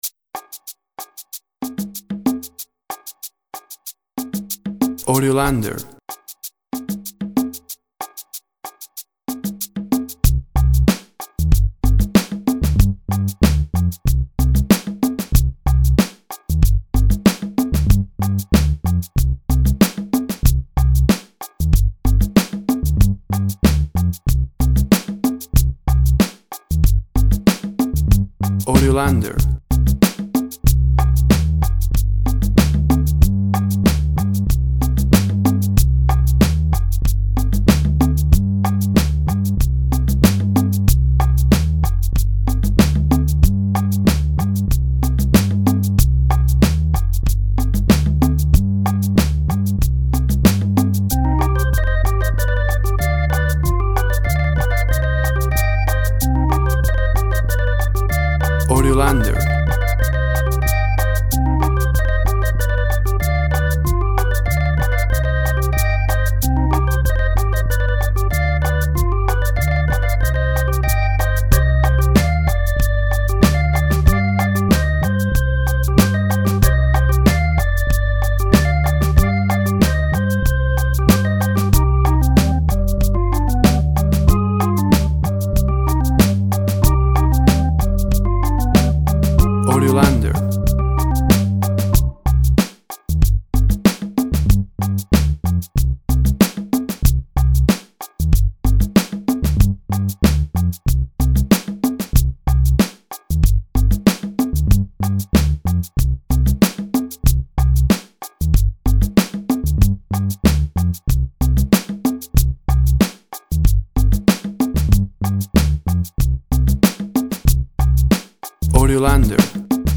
Slow emotional latín Flow.
Tempo (BPM) 95